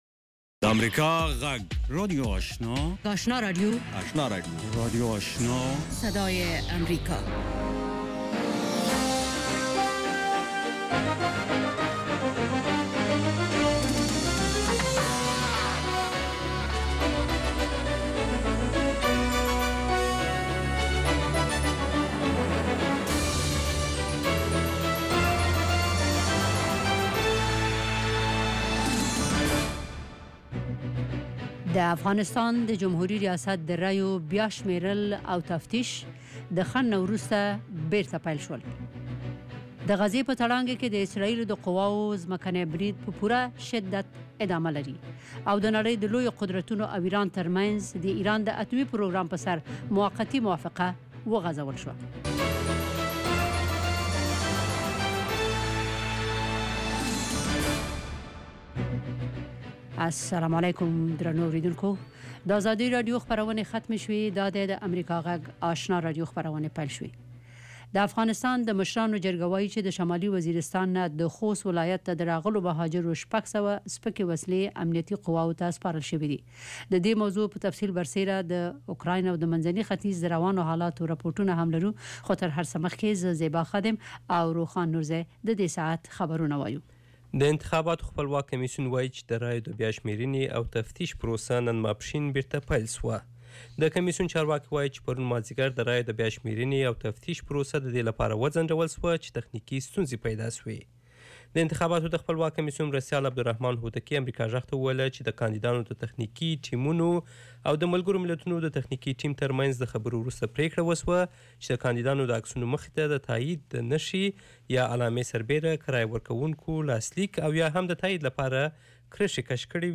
یو ساعته پروگرام: د ورځې د مهمو سیاسي، اجتماعي او نورو مسایلو په اړه له افغان چارواکو او کارپوهانو سره خبرې کیږي. د اوریدونکو پوښتنو ته ځوابونه ویل کیږي. ددغه پروگرام په لومړیو ١٠ دقیقو کې د افغانستان او نړۍ وروستي خبرونه اورئ.